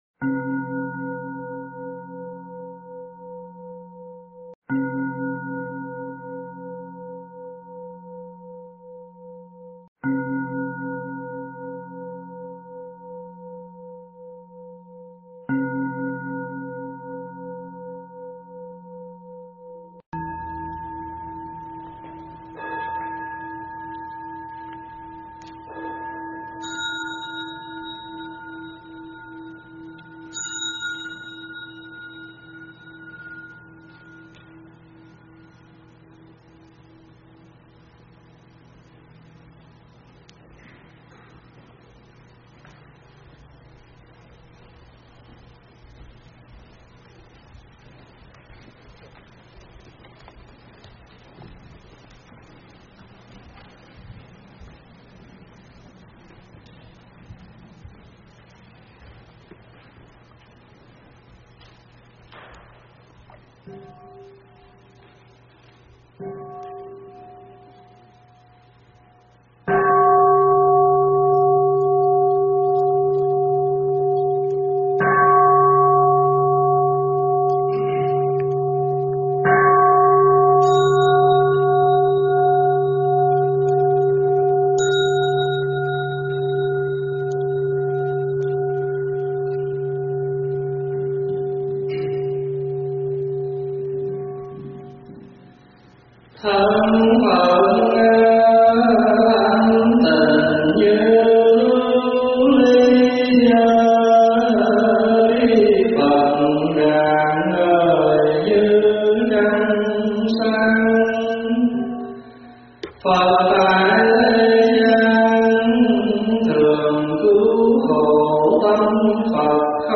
Mp3 Tụng Chú Đại Bi